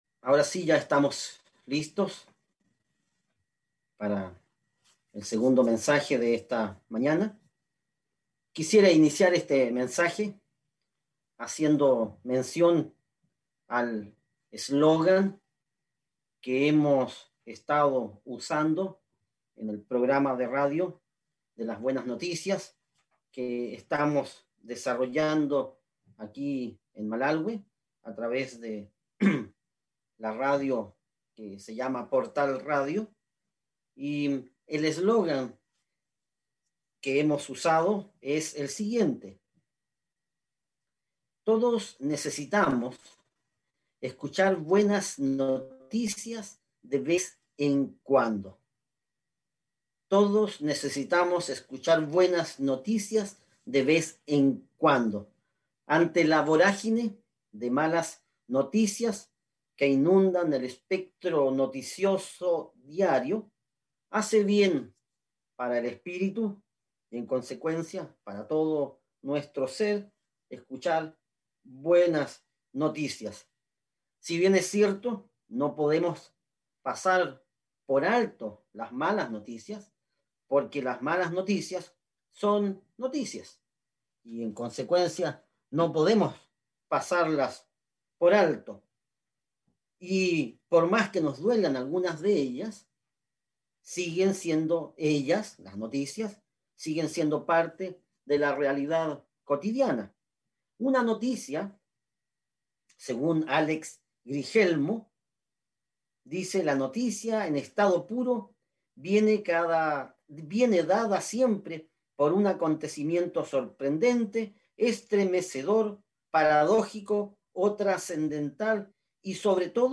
Mensaje entregado el 20 de febrero de 2021.